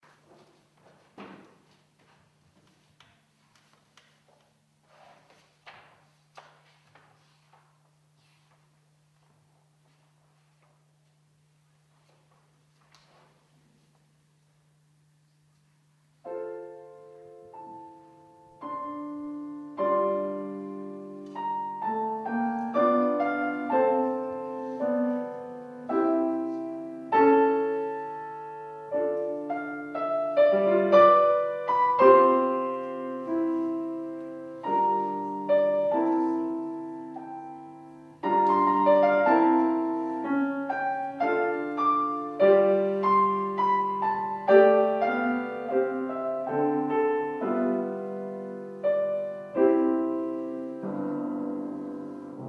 II OTTOBRE MUSICALE A PALAZZO VALPERGA, 17 ottobre 2009 - Concerto - Arie Italiane - P. MASCAGNI Intermezzo da Cavalleria rusticana pianoforte solo